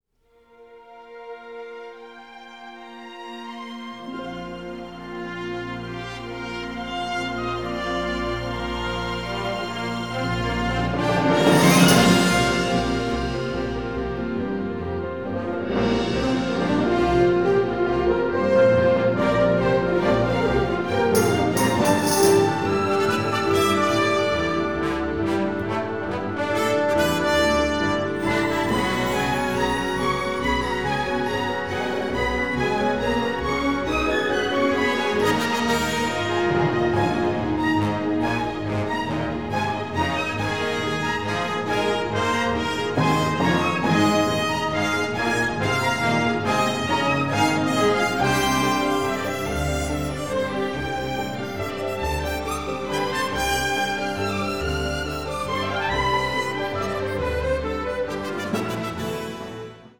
emotional, symphonic Americana score